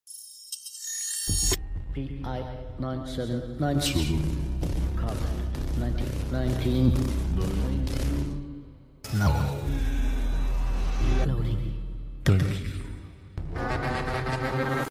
Artefakt gestoßen. Es handelt sich um eine isolierte Tonspur